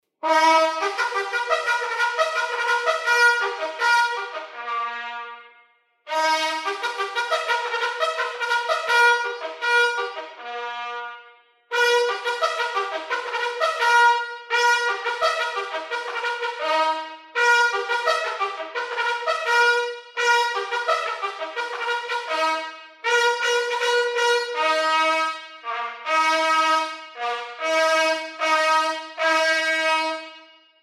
Trompetsignalen – 63-6 Trompetterkorps der Cavalerie (Muziekkorps Huzaren van Boreel)
Hieronder de meest voorkomende trompetsignalen die tijdens de diensplicht van het 63-6 Trompetterkorps der Cavalerie dagelijks gespeeld werden: